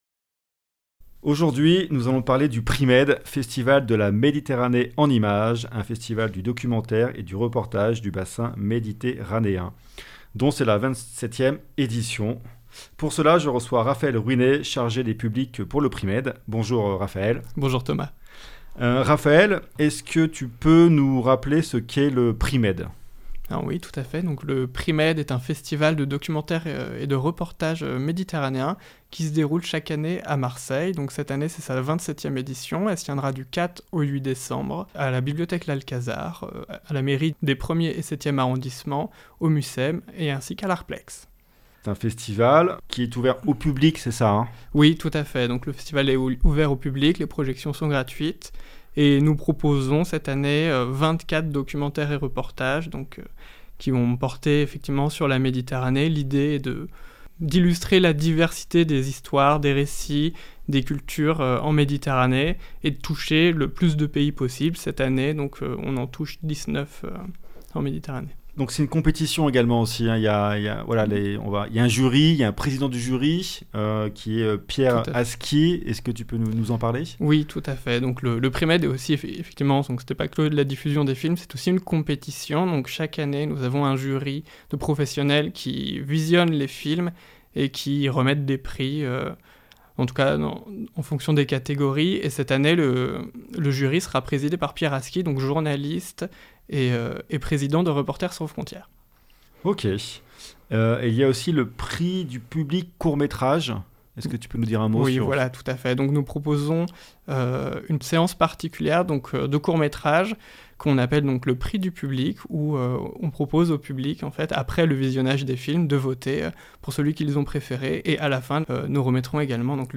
Du 4 au 8 décembre 2023 à Marseille se tient le PriMed, festival du documentaire et du reportage du bassin méditérranéen Nous recevons dans nos studios de Marseille